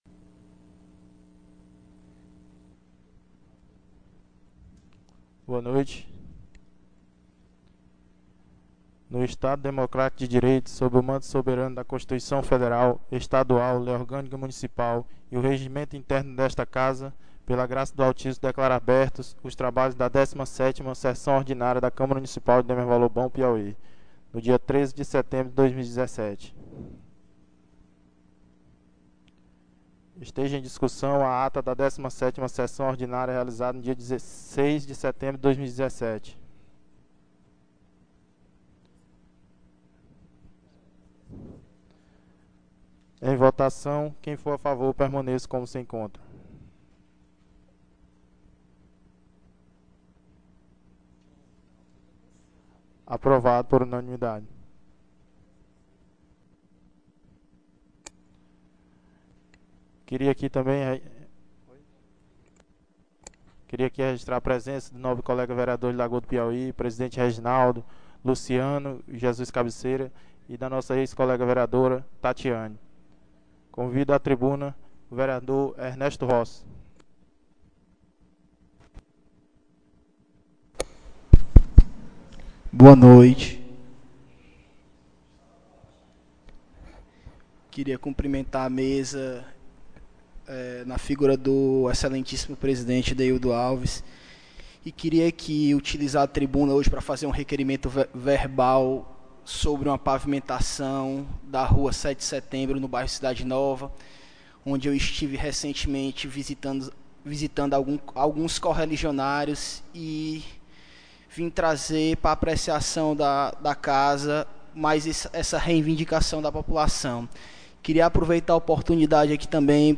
18ª SESSÃO ORDINÁRIA 13/09/2017